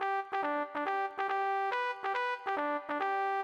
曲 号音
トランペット独奏